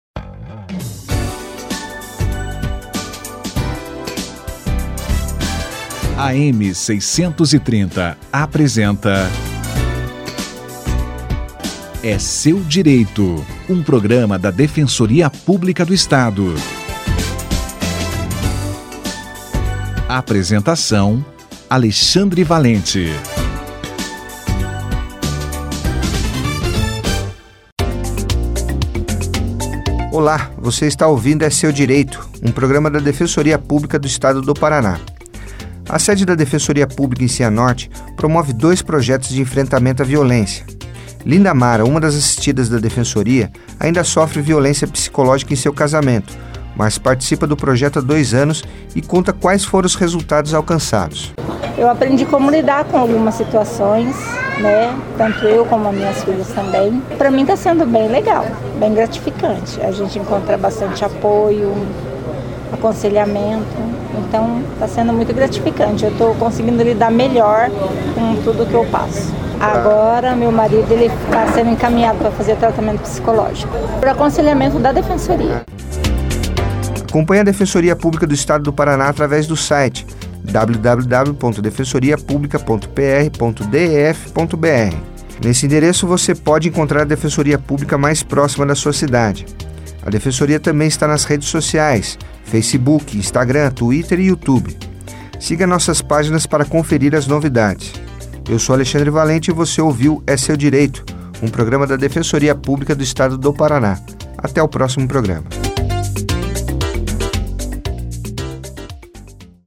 13/12/2018 - Participante de projeto contra violência doméstica da DPPR em Cianorte conta sua experiência